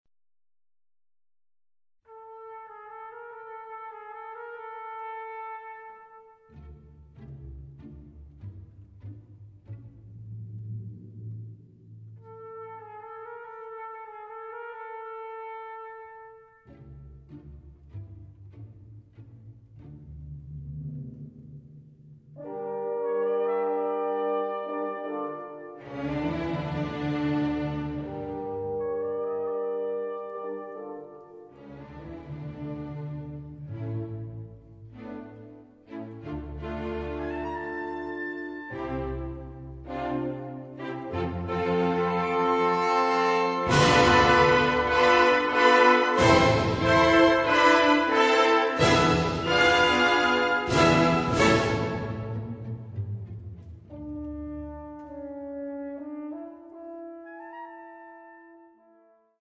The recording was made in Budapest, in 1984..